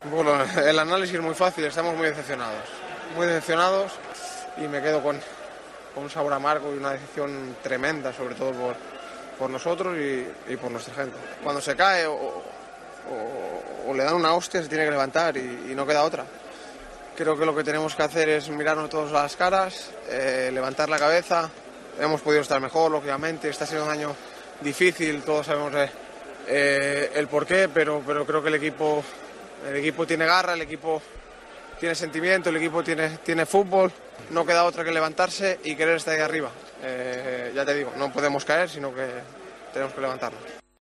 El futbolista del Sevilla habló para los medios del club tras la eliminación del Sevilla ante el West Ham en la Europa League.